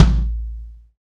KIK XR.BD02L.wav